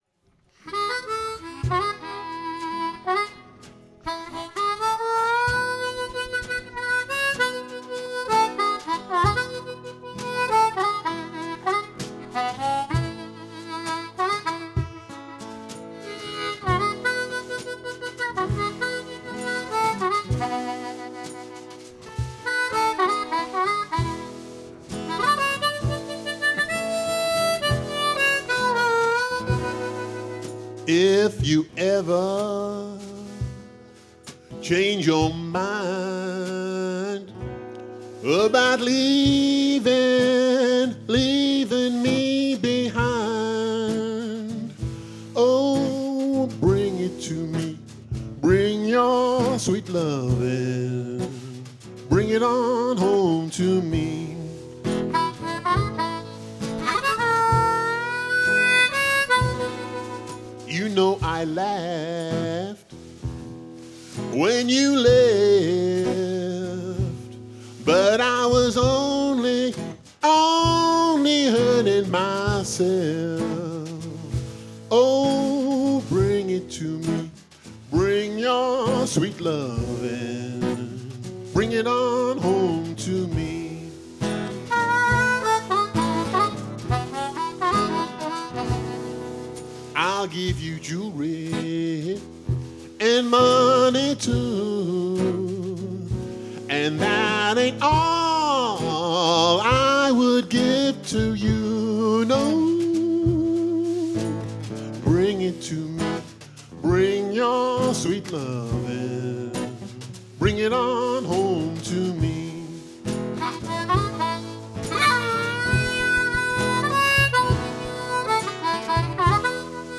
Live performance 6th July 2018 – M.A.D.E. @ No 18 Open Mic